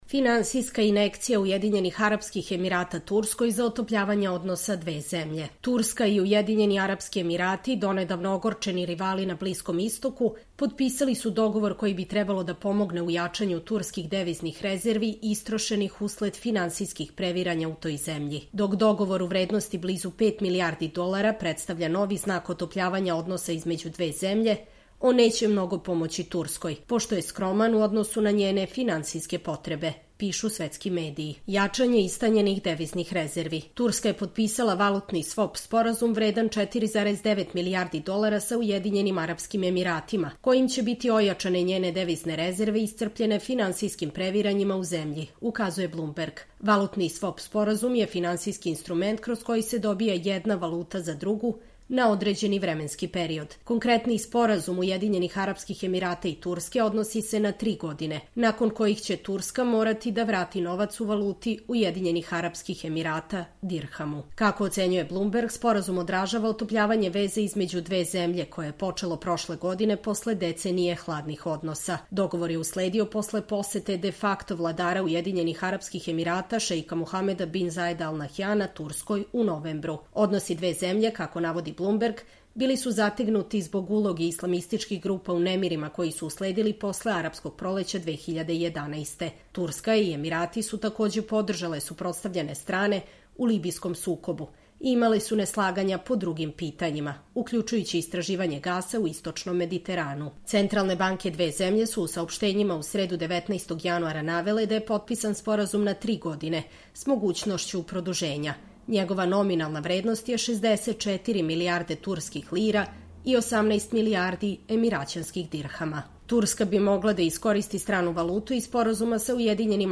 Čitamo vam: Finansijska injekcija UAE Turskoj za otopljavanja odnosa dve zemlje